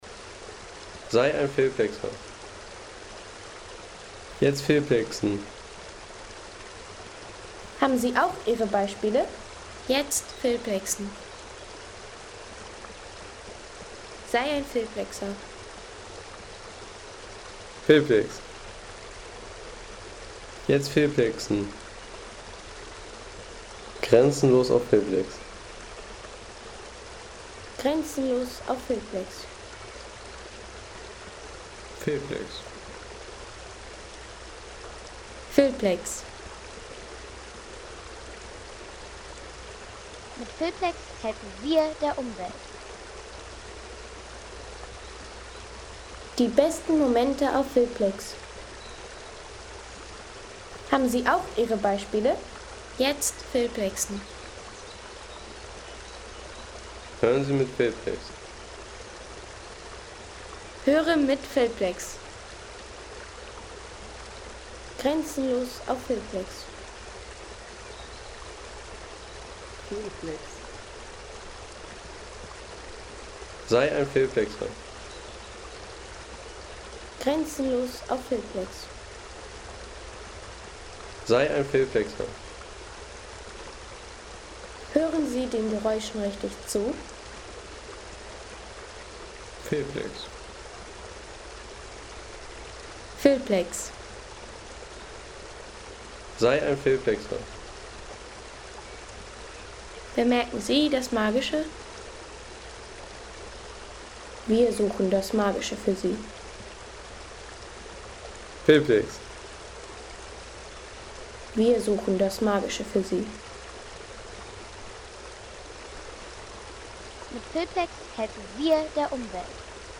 Wasserbach im Genner Hoel Wald
Der Wasserbach im Genner Hoel Wald bei Aabenraa in Dänemark, ist bequ ... 3,50 € Inkl. 19% MwSt.